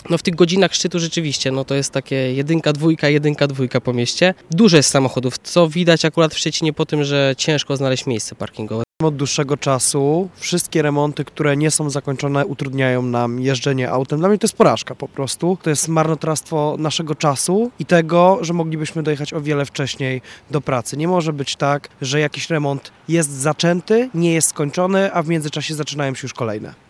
Jak mówią sami zainteresowani, jazda po Szczecinie staje się coraz większym wyzwaniem: